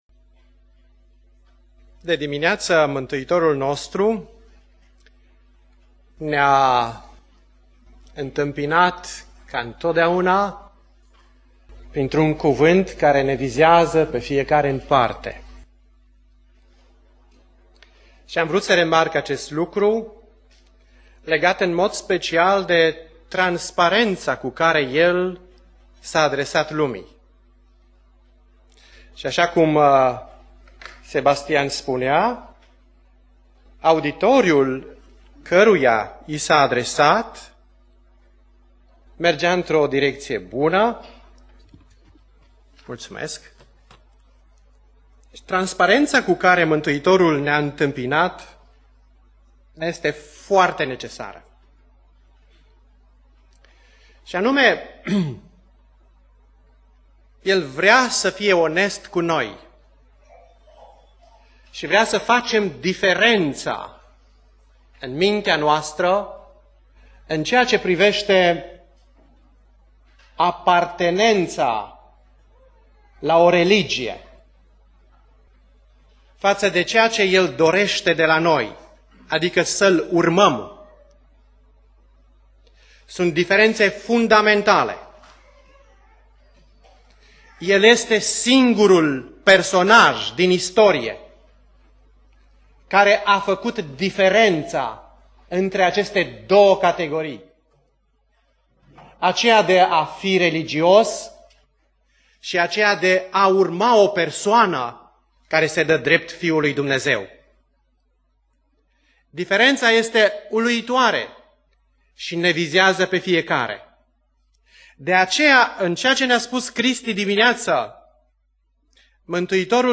Predica Aplicatie Eclesiastul 9:10-10:20